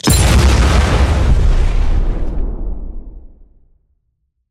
На этой странице представлены звуки мин – от глухих подземных взрывов до резких срабатываний нажимных механизмов.
Звуковой эффект Мина - Вариант 3